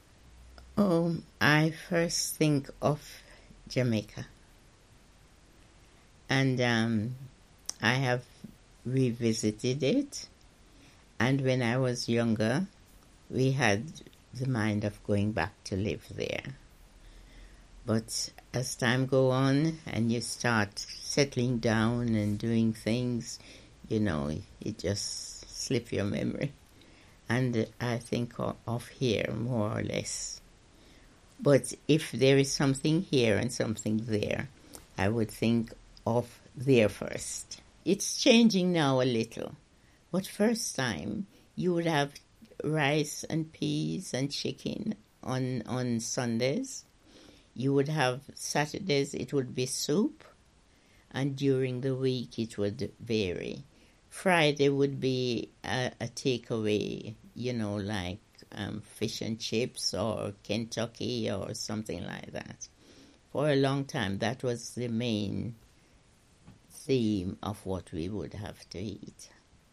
We worked with photographer Vanley Burke and older African-Caribbean women to create Home.